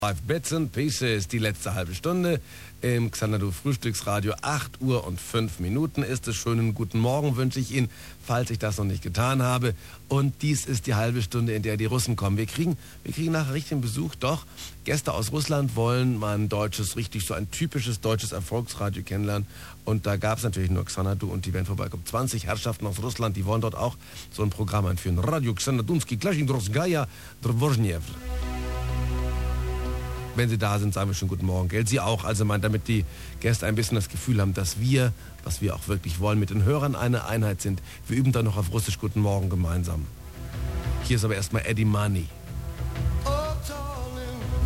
Dieser Mitschnitt stammt von 29.09.1985 Nr. 1116: Interessant, die Tatsache, dass Radio C aus dem Studio von Radio Aktiv sendet und es (wiedermal) nicht klar war, wer/wo/wielange auf dieser heissbegehrten Frequenz on air sein darf.
Radio C - Radio M 1 Übergang auf 92,4 :